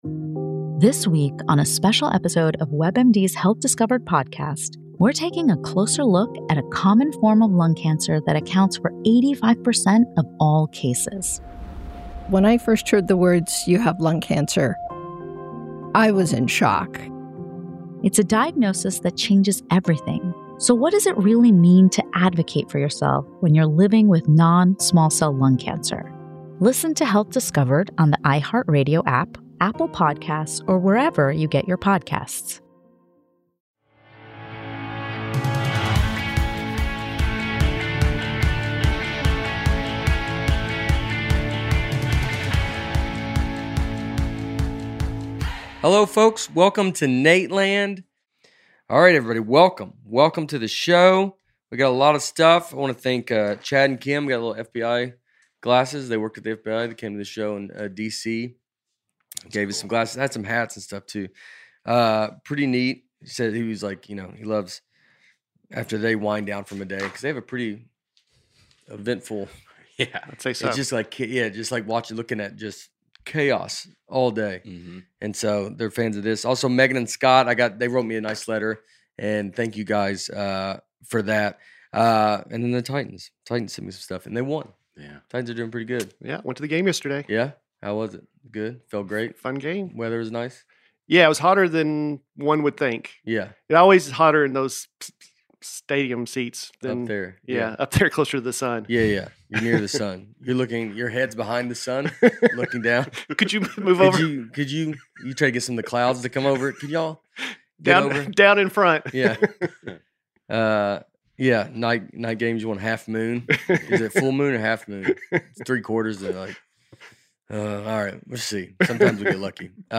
On this week's podcast, we're once again learning about animals. The guys debate whether a whale would deliberately swallow a human, can a jellyfish really be immortal, and what really happens at the blessing of the pets.